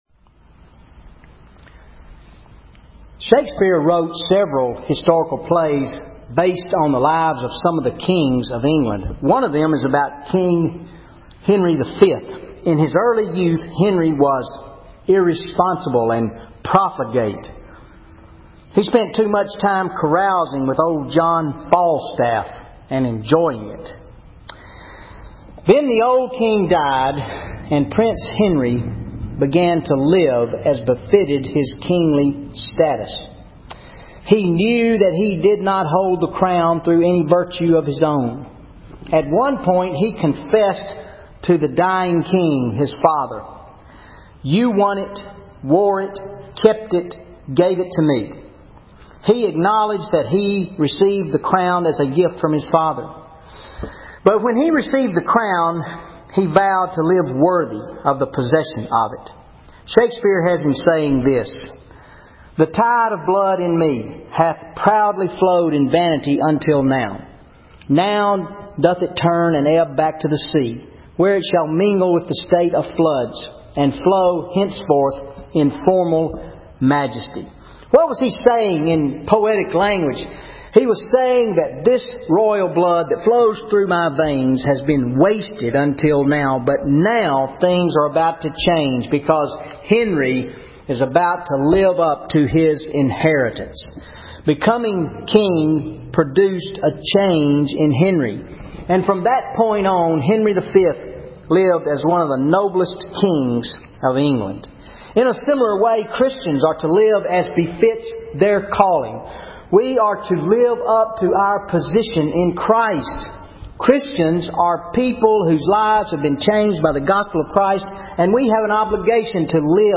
Wednesday Night Bible Study 09/11/13 Philippians 1:27-28 How Can I Live Befitting the Gospel of Jesus Christ?